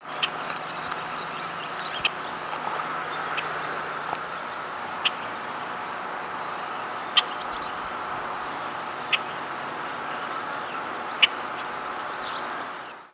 Boat-tailed Grackle diurnal flight calls
Female in flight with Barn Swallow calling in the background.